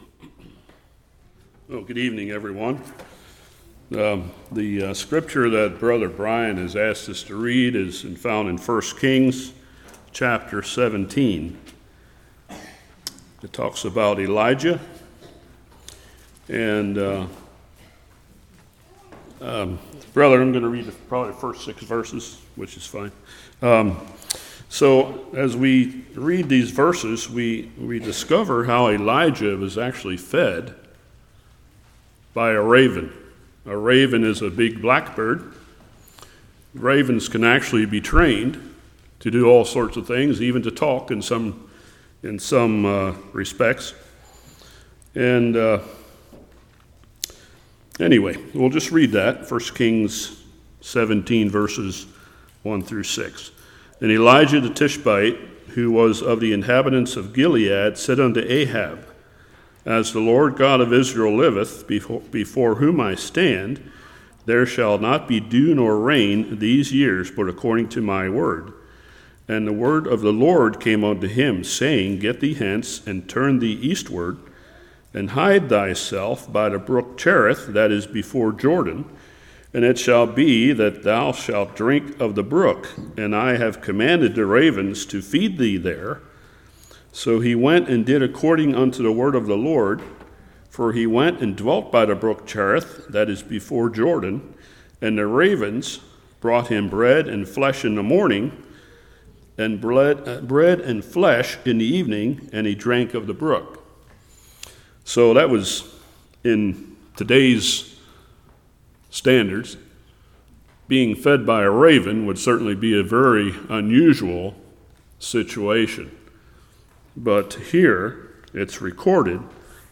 1 Kings 17:1-6 Service Type: Evening Subject to Like Passions The spirit Of Baal Is Alive today Yawee Is My God Do You Trust God?